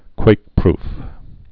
(kwākprf)